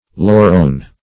\Lau"rone\